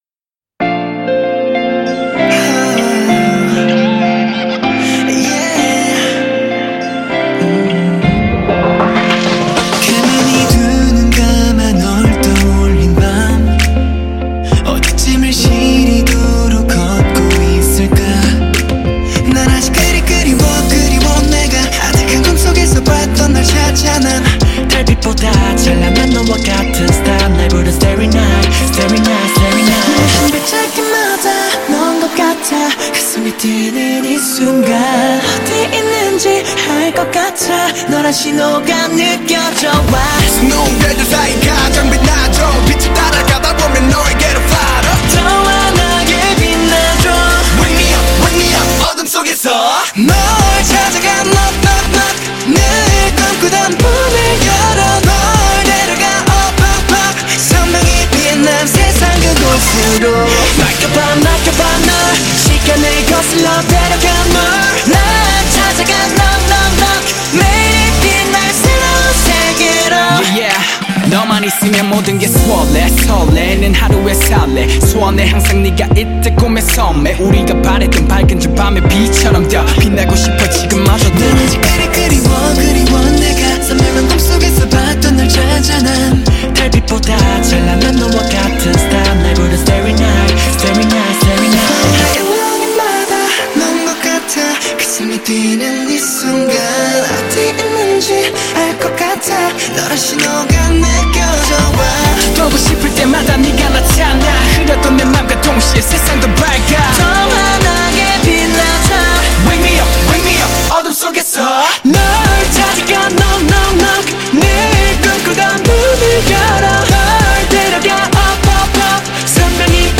سبک: پاپ